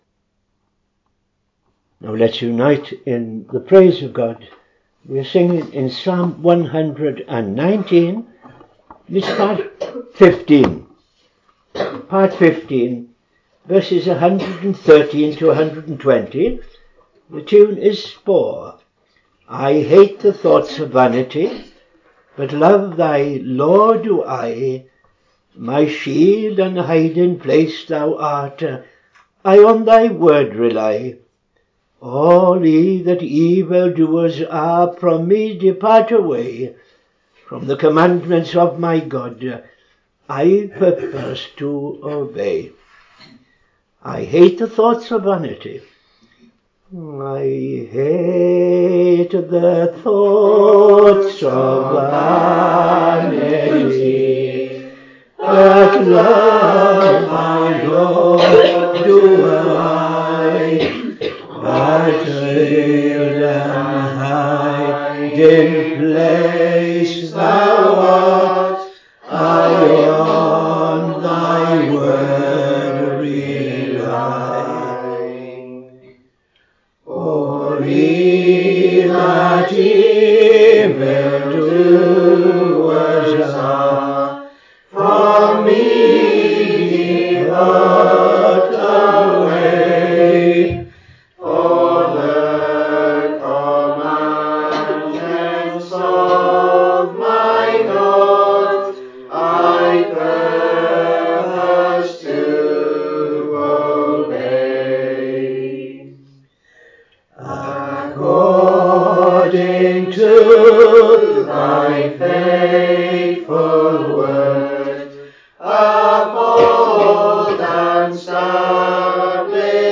Morning Service - TFCChurch
Public Prayer followed by N.T. Reading Mark 7:1-37